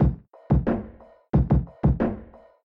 消音的Lofi Boom Bap节拍
描述：简单的哑巴声音LOFI节拍说，如果使用和你的想法是什么
标签： 90 bpm Hip Hop Loops Drum Loops 459.61 KB wav Key : Unknown
声道立体声